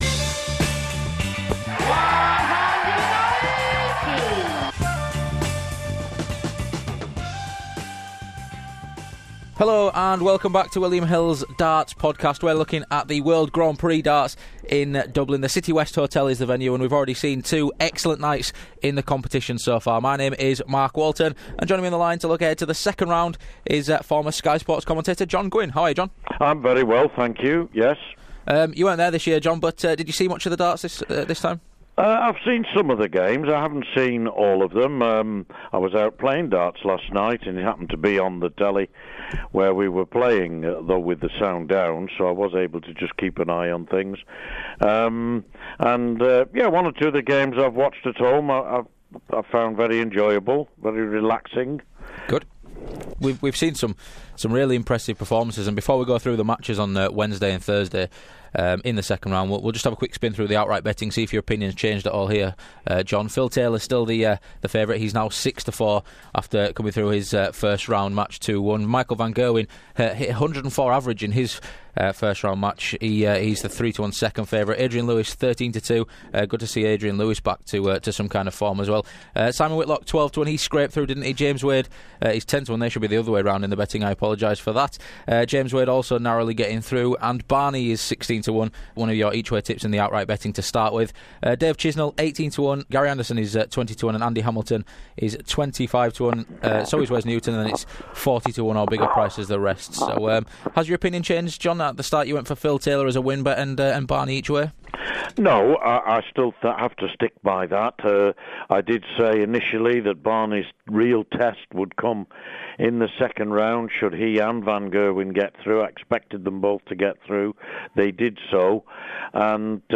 With Taylor, van Gerwen, Lewis, van Barneveld and the like all progressing it's set up to be a tremendous round of sixteen and former Sky Sports commentator John Gwynne looks ahead to the action.